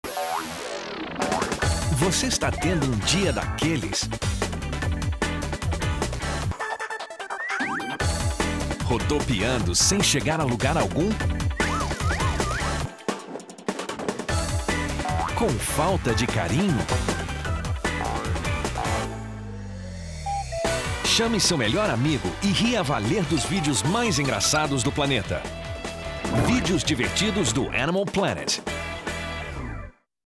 Masculino